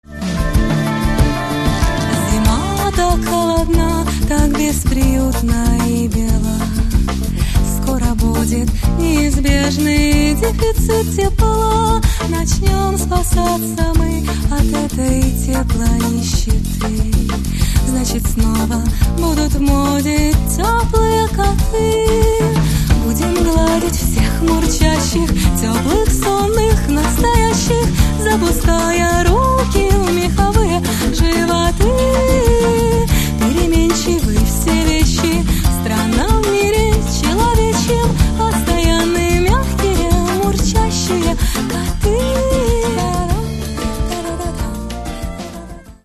Каталог -> Рок и альтернатива -> Лирический андеграунд